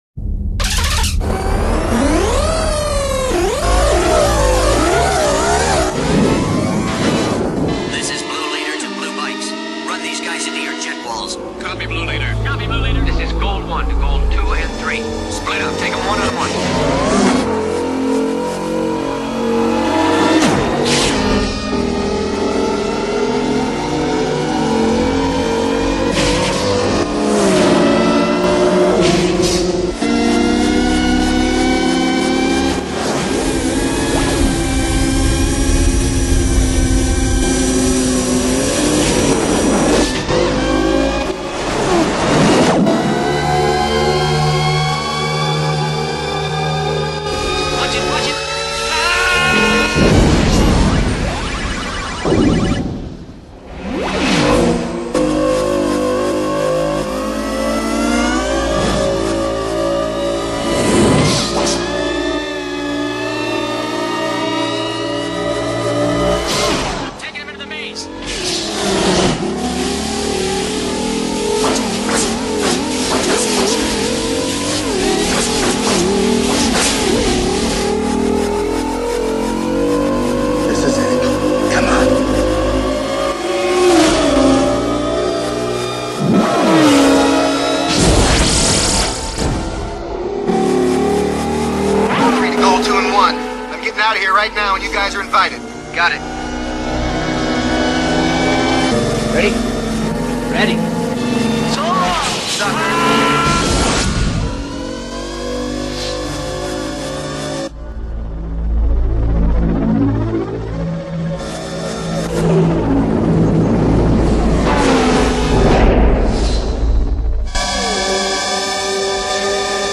Audio QualityCut From Video